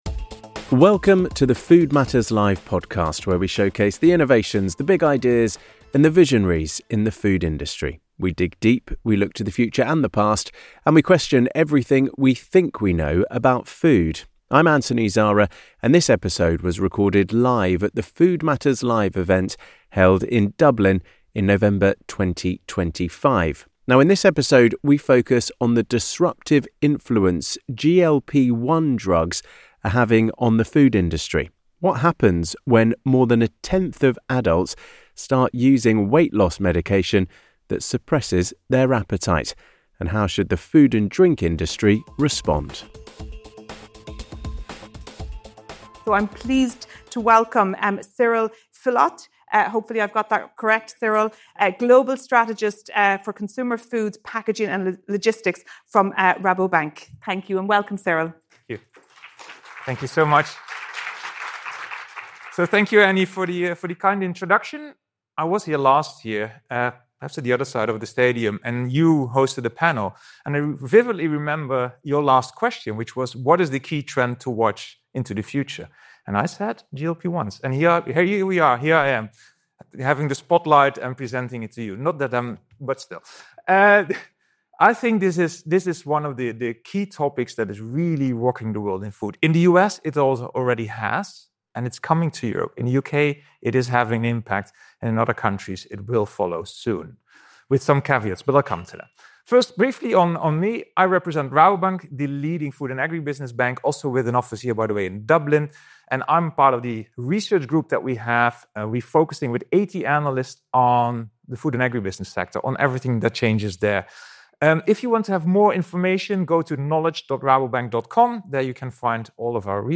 In this episode of the Food Matters Live podcast, recorded at our event in Dublin in November 2025, we look at how GLP-1 drugs like Ozempic and Wegovy are already leading to retailers and manufacturers all over the world fundamentally rethinking their strategies.